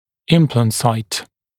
[‘ɪmplɑːnt saɪt] [‘импла:нт сайт] место установки имплантата, место для установки имплантата